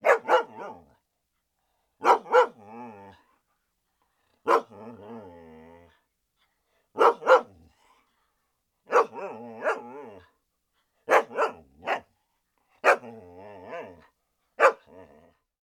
SND_dog_single_rnd_01.ogg